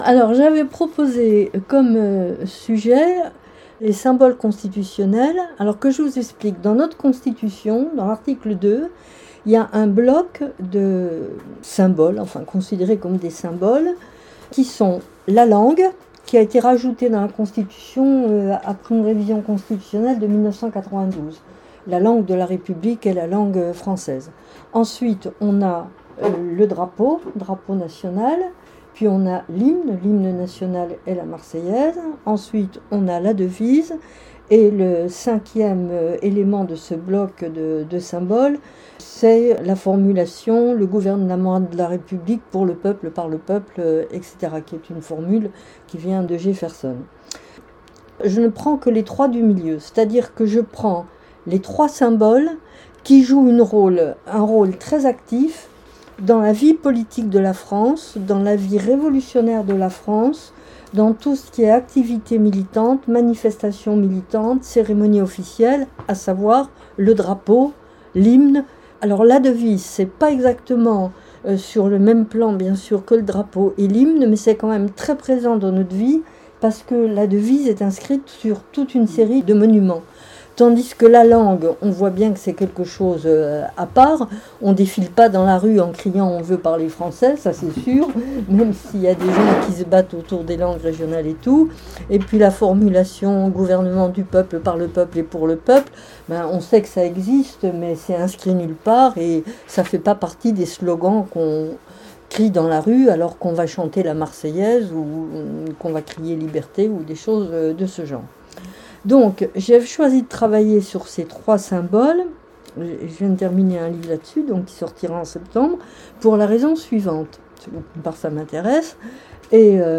Elle consacre cette conférence à la devise Liberté, Égalité, Fraternité.